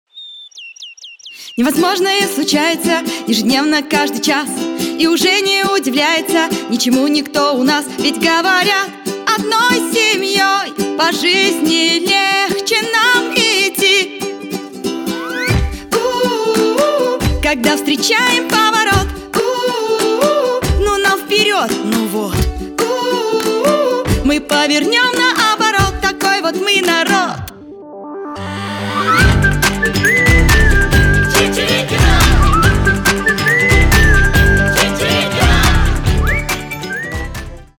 пение птиц , свист
детская музыка